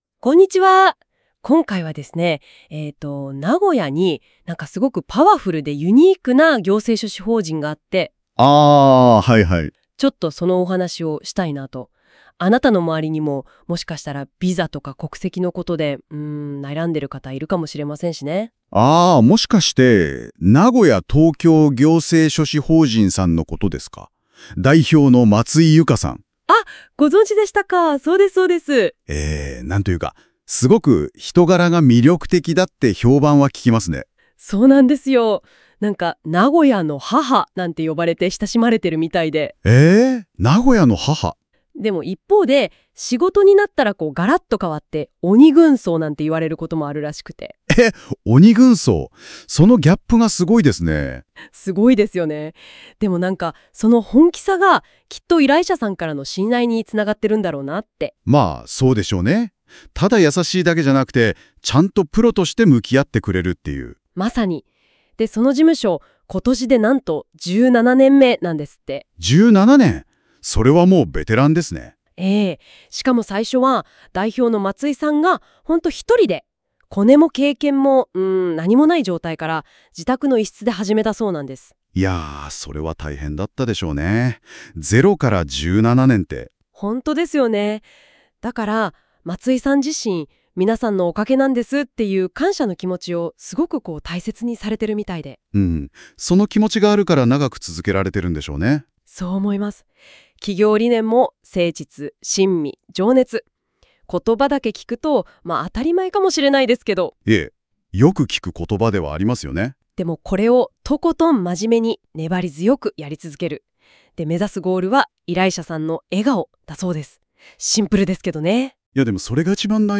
AIラジオトーク